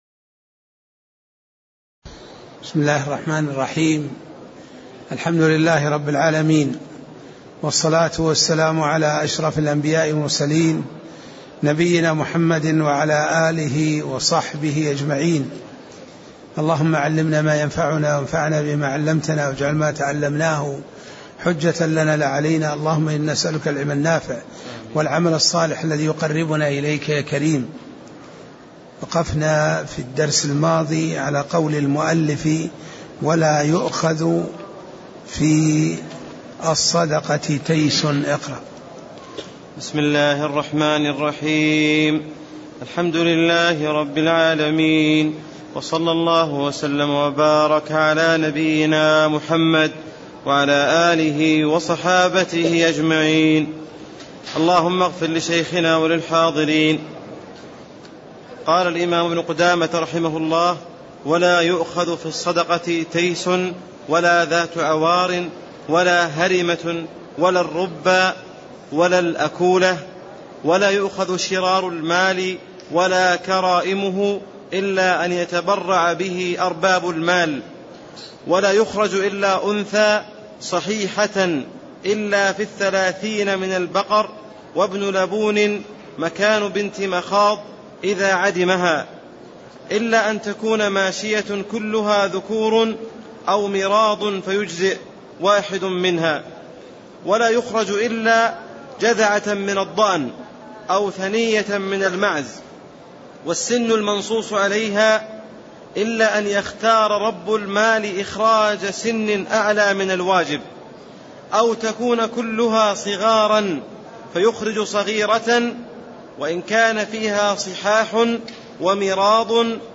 تاريخ النشر ٦ شعبان ١٤٣٤ هـ المكان: المسجد النبوي الشيخ